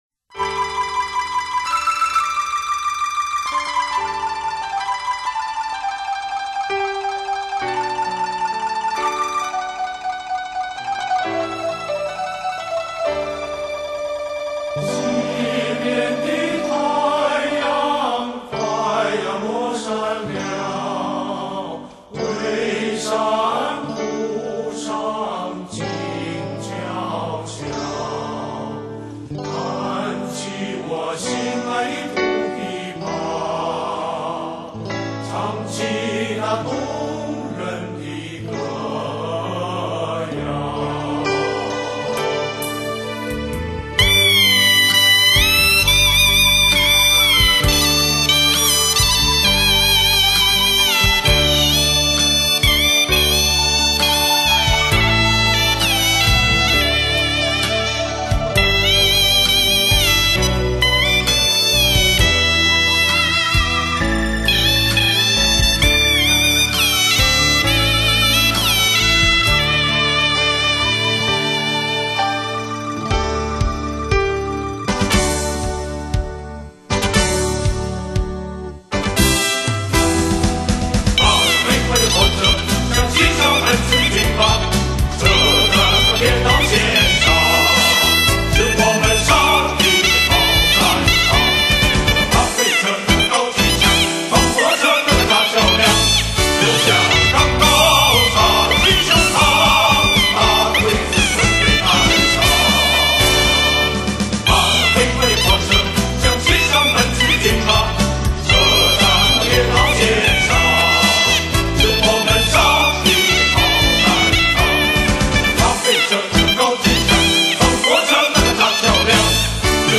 影视经典音乐
唢呐演奏